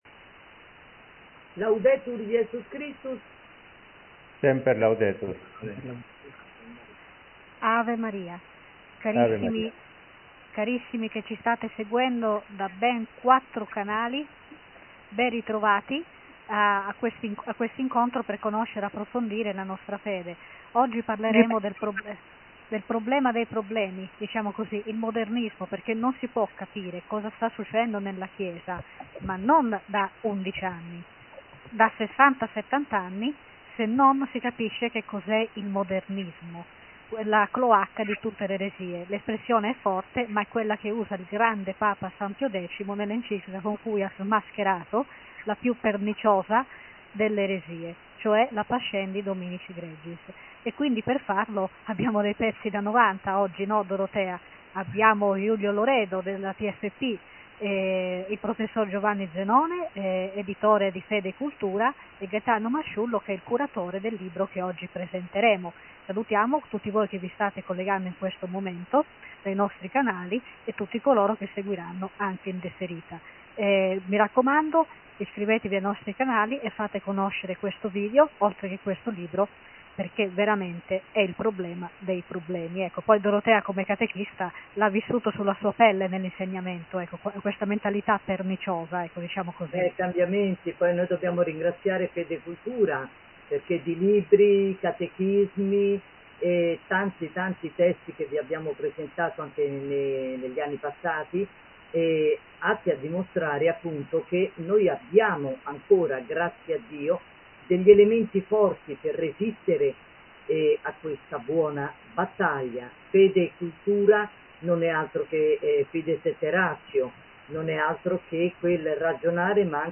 Catechesi del venerdì